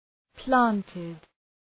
Προφορά
{‘plæntıd}